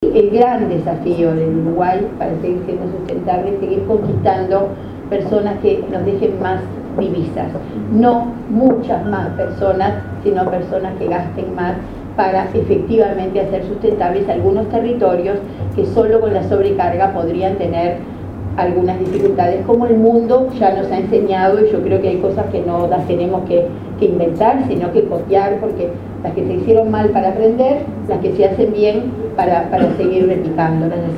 En el marco del foro Turismo y Desarrollo Sustentable, la ministra del área, Liliam Kechichian, sostuvo, este miércoles 19, que el gran desafío de Uruguay es captar “no muchos más visitantes, sino personas que gasten más”. La actualización del Plan Estratégico al 2030 “es el mejor aporte que podremos dejar” al próximo gobierno, añadió la jerarca, en la actividad realizada en el anexo del Palacio Legislativo.